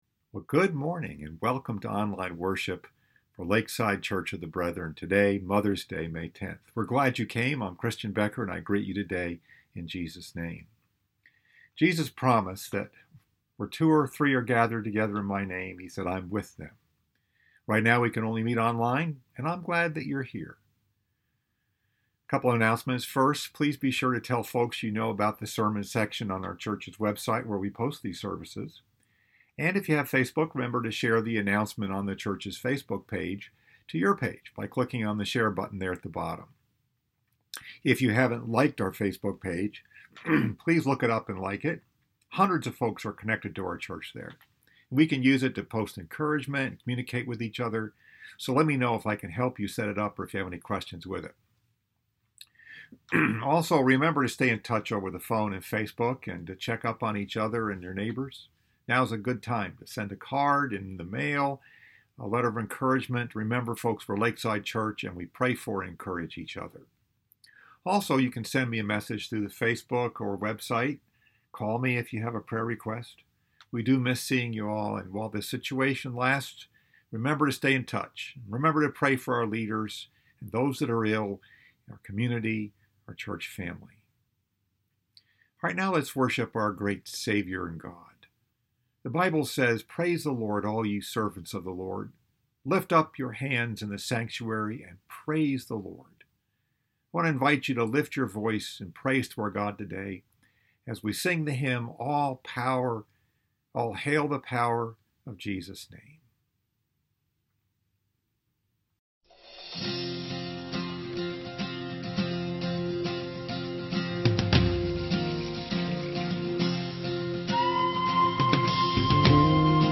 Message: “A Great Mother” Scripture: 2 Kings 4